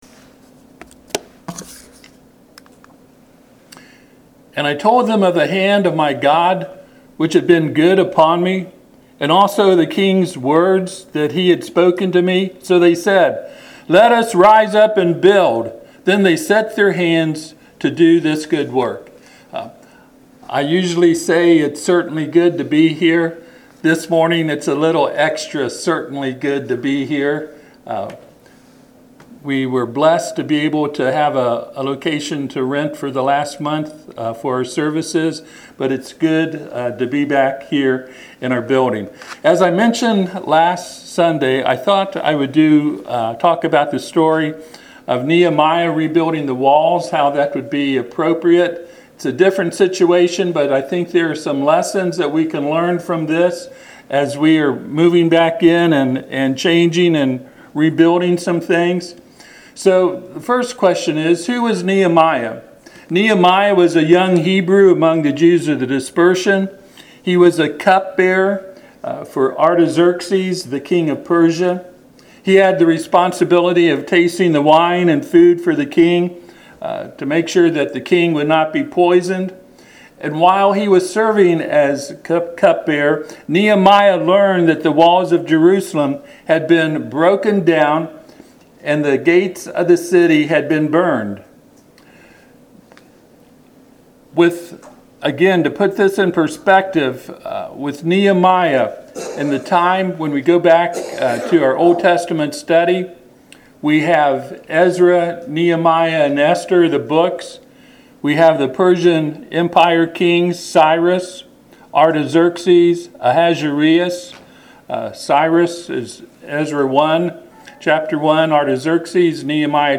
Passage: Nehemiah 2:18 Service Type: Sunday AM